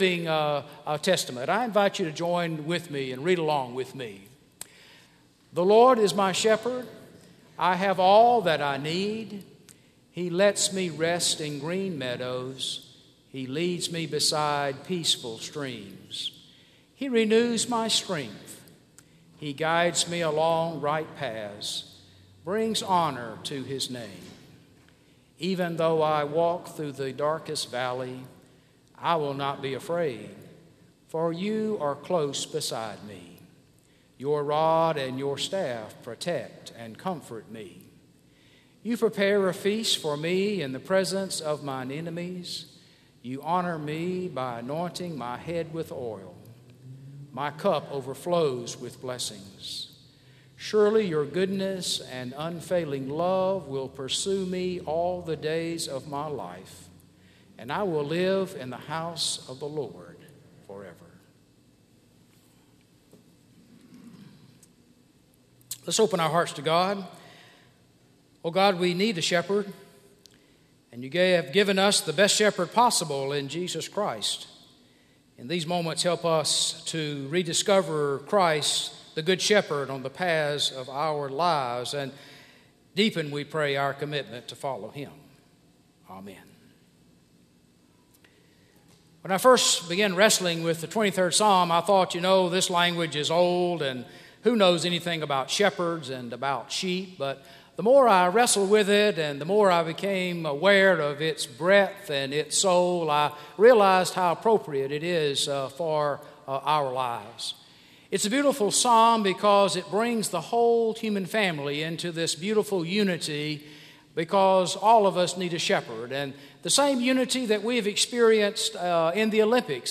A message from the series "Psalm 23."